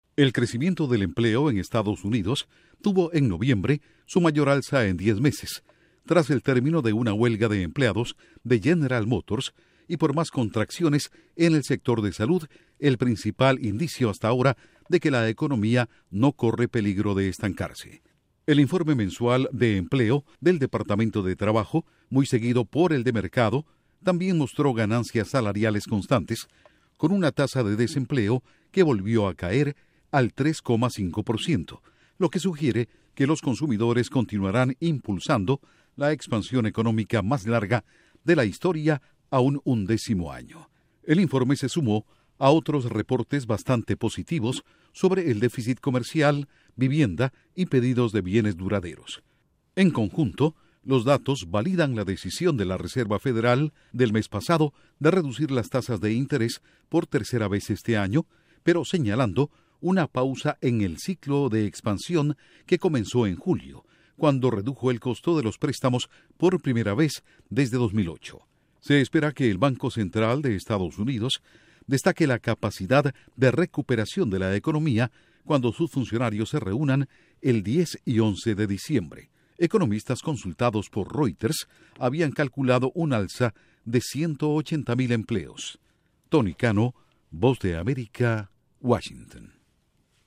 ECONOMÍA: Crecimiento empleo Estados Unidos se acelera a mayor tasa en 10 meses. Informa desde la Voz de América en Washington